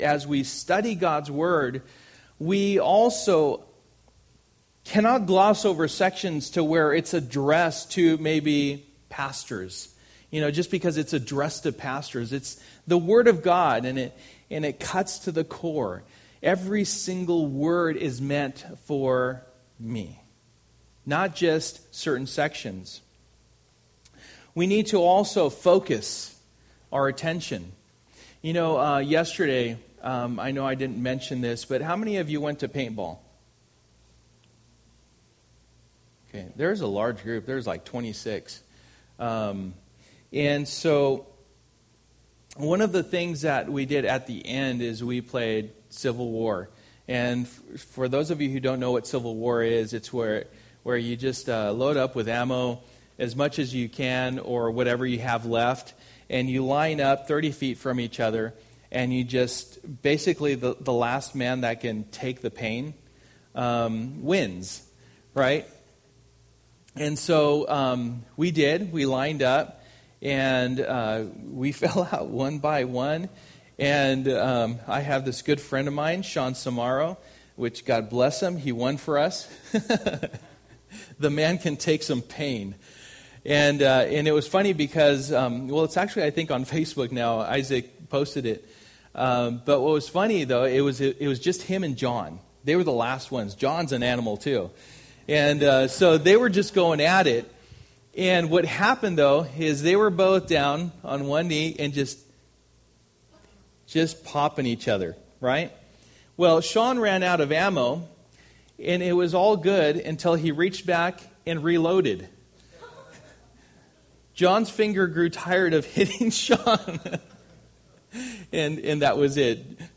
Titus 1:1-16 Service: Sunday Morning %todo_render% « Identification Order in the Home Order in the Church